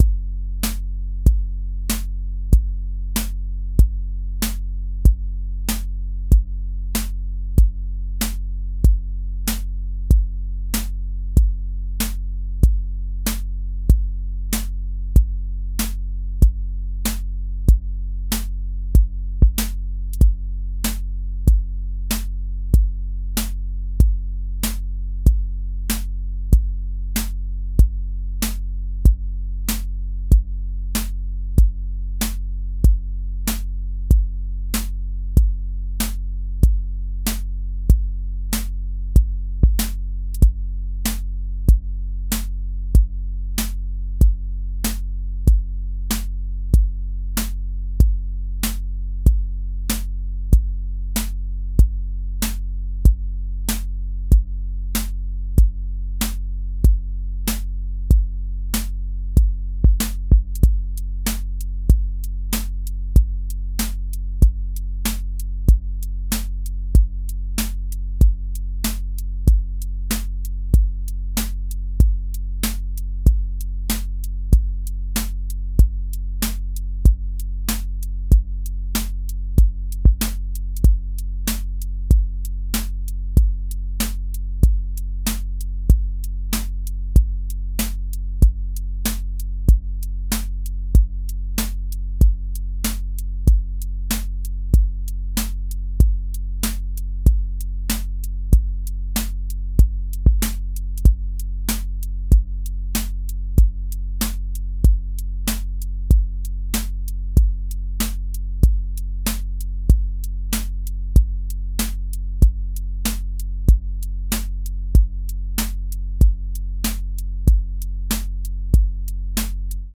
120-second Boom Bap
• voice_kick_808
• voice_snare_boom_bap
• voice_hat_rimshot
A 120-second boom bap song with a lifted loop section, a stripped verse section, a variant bridge, and a clear outro return. Use recurring drum, sub, and counter-rhythm patterns that recombine differently by section so the form feels like a song, not one loop.